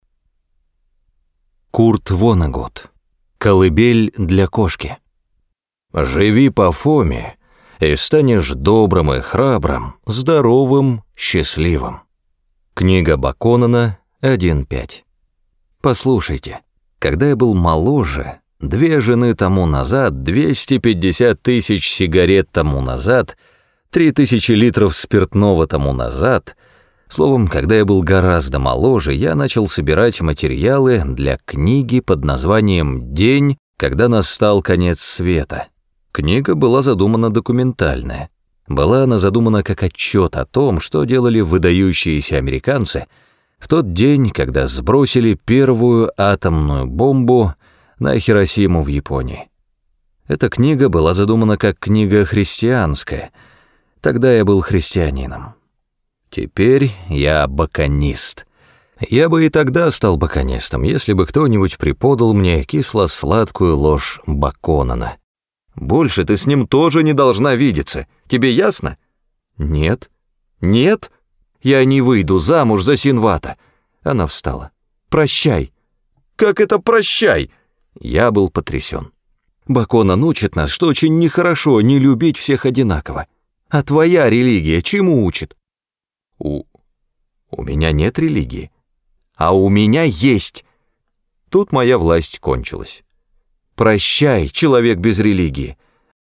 Раздел: Аудиокниги